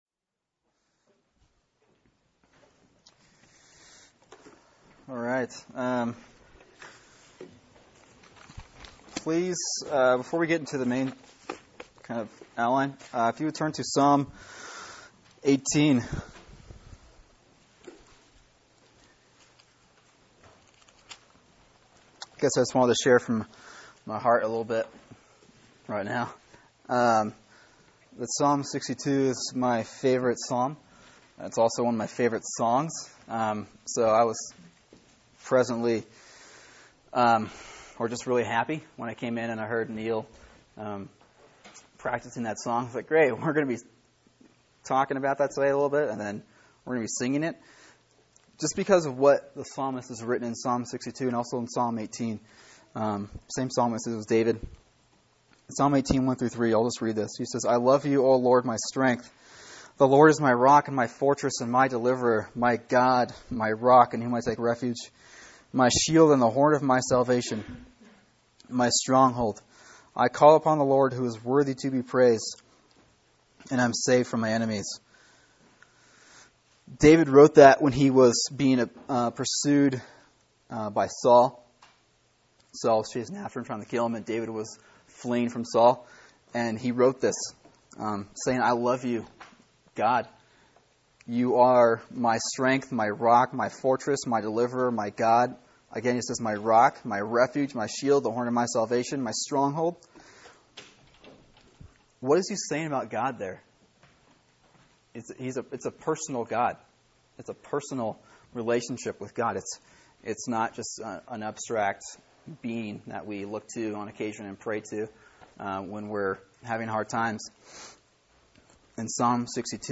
[sermon] Matthew 5:9 “Peacemaking” | Cornerstone Church - Jackson Hole